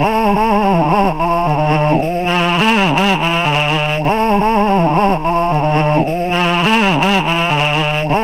AFRIK FLUTE3.wav